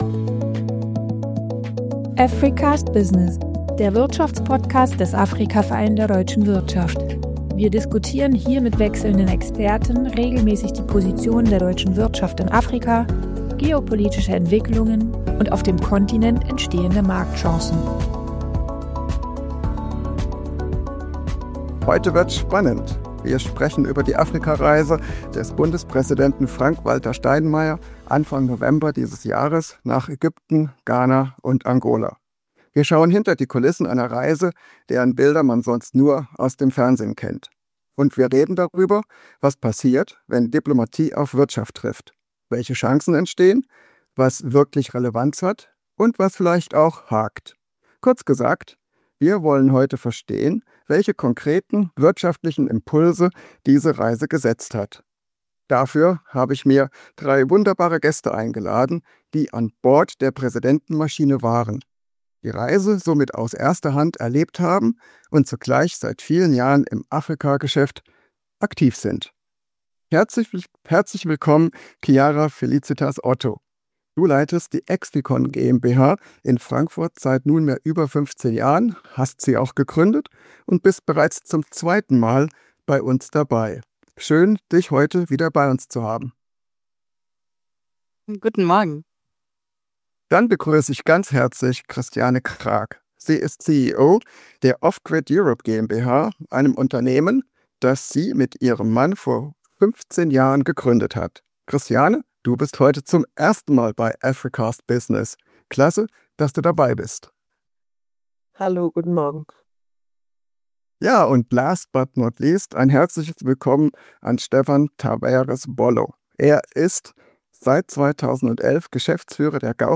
ES ERWARTET SIE EINE DISKUSSION: